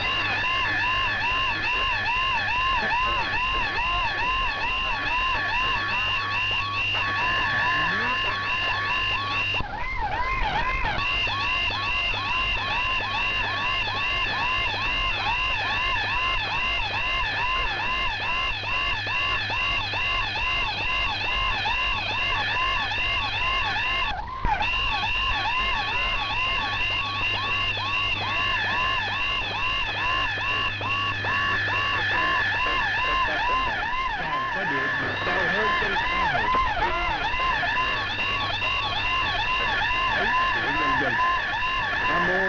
Vietnamese_siren_jammer.wav